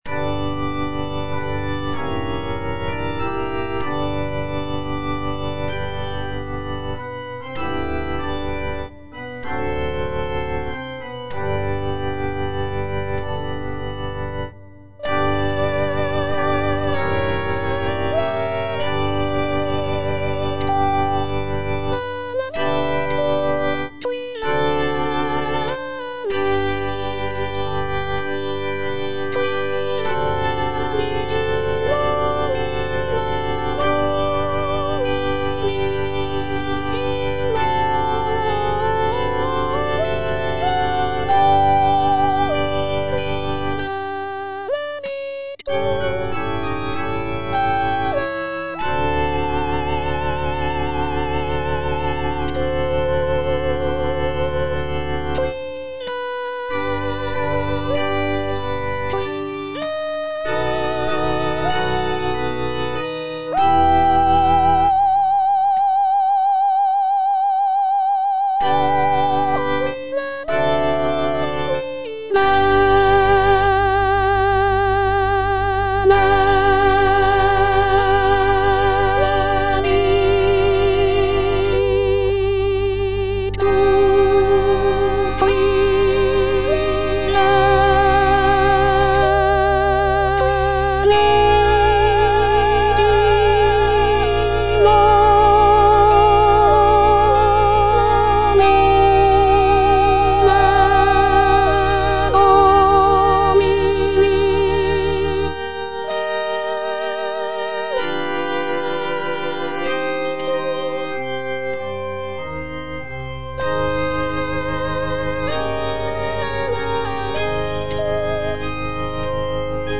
C.M. VON WEBER - MISSA SANCTA NO.1 Benedictus - Soprano (Voice with metronome) Ads stop: auto-stop
The work features a grand and powerful sound, with rich harmonies and expressive melodies.